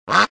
Duck Quack 3 Sound Effect Free Download
Duck Quack 3